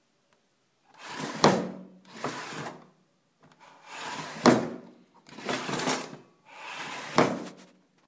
打开和关闭厨房的抽屉
描述：用刀子和叉子打开厨房的抽屉
Tag: 关闭 厨房 开放式 开放式 抽屉